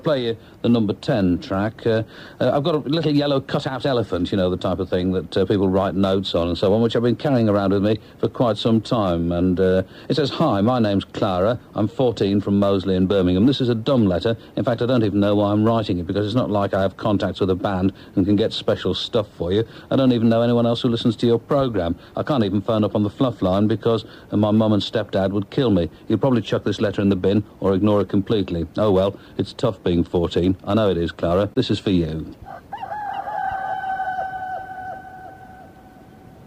This intro to track #10 in the 1990 Festive 50 nails exactly why I loved John Peel.